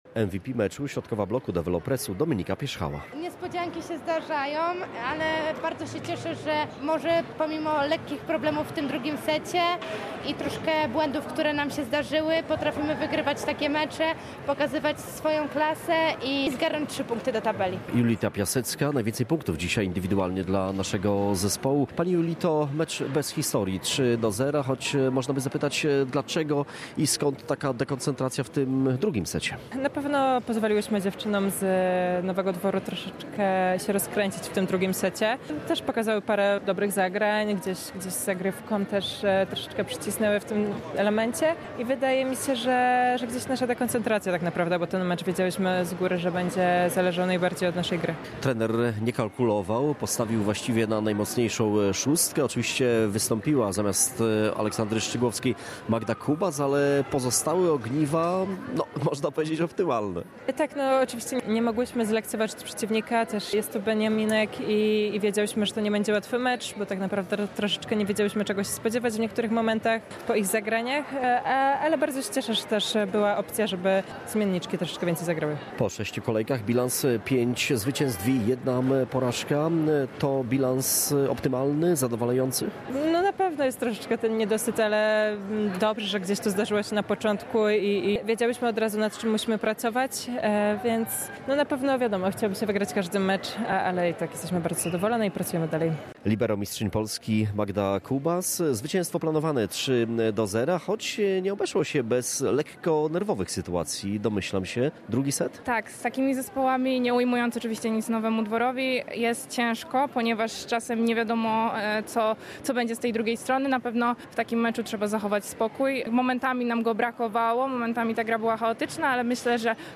Pomeczowe rozmowy